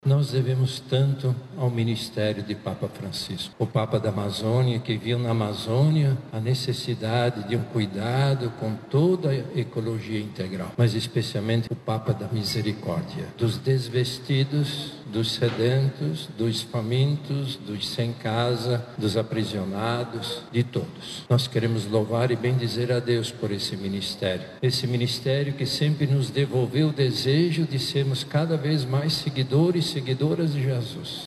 A celebração eucarística foi presidida pelo Arcebispo Metropolitano de Manaus e Cardeal da Amazônia, Dom Leonardo Steiner, que iniciou a Santa Missa destacando a forte contribuição do Papa para a Amazônia.